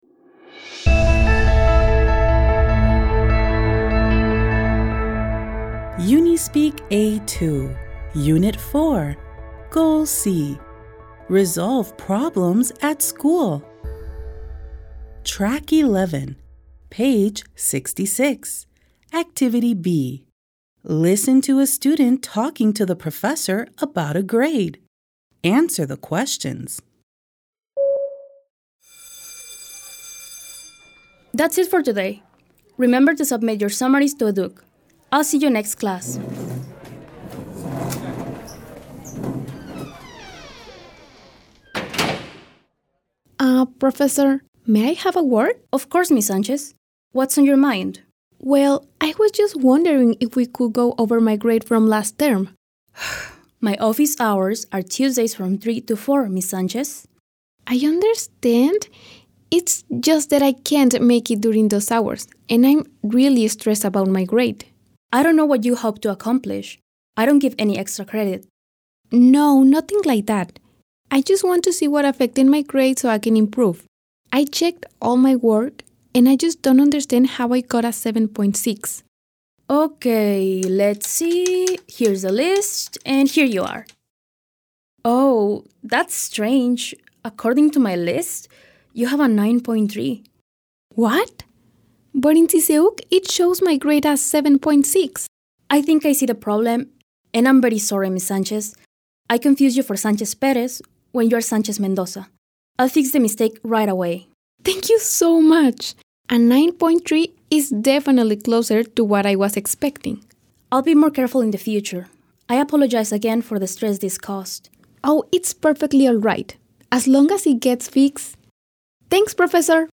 Listen to a student talking to the professor about a grade.